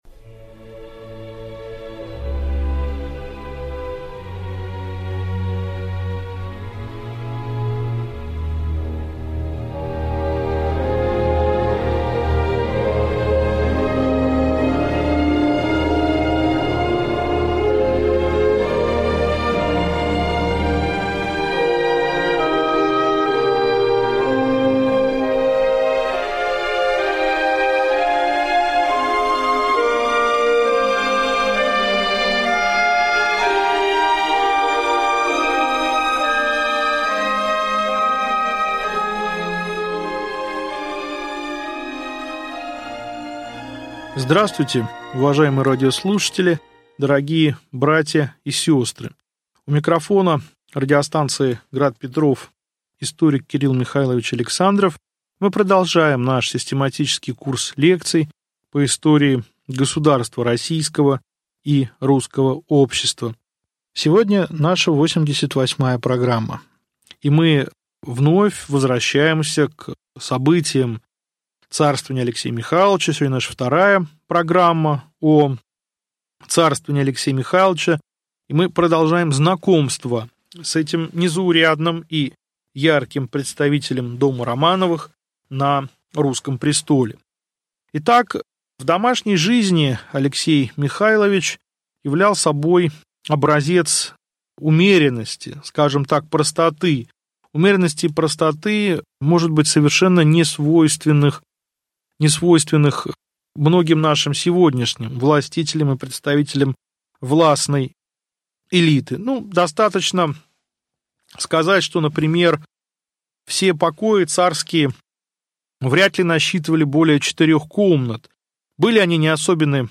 Систематический курс лекций по русской истории.